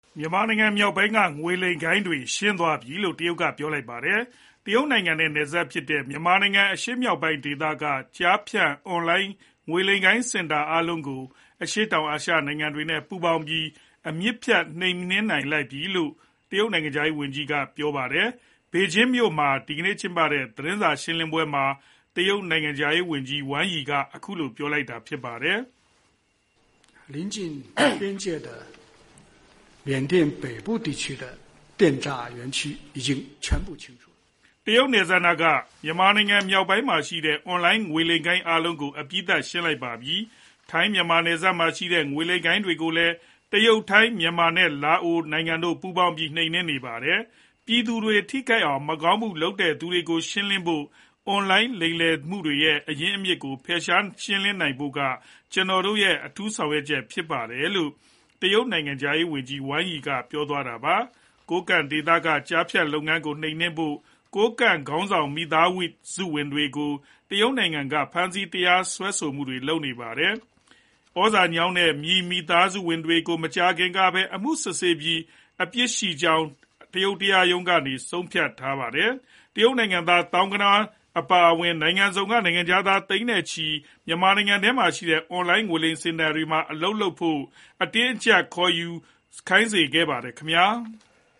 တရုတ်နိုင်ငံခြားရေးဝန်ကြီး Wang Yi ဘေဂျင်းမြို့သတင်းစာရှင်းလင်းပွဲ။ (မတ်လ ၇၊ ၂၀၂၅)